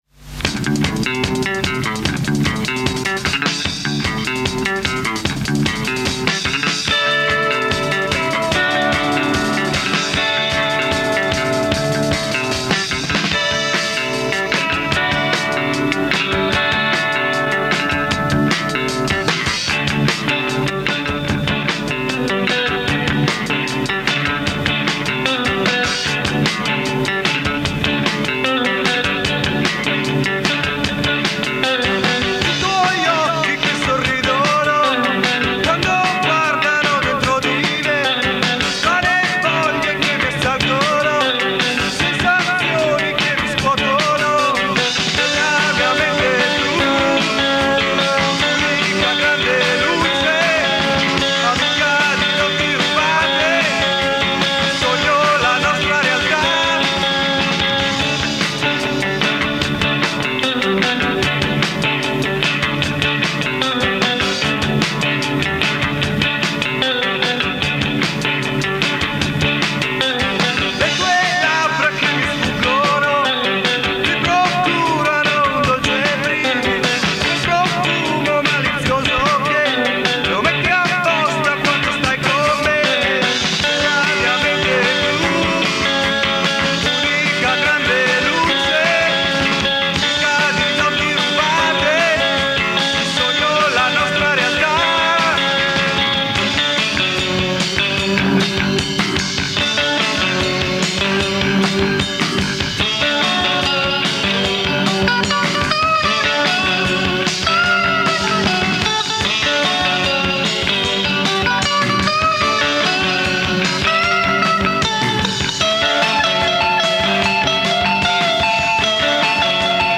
da un demotape del 1986. https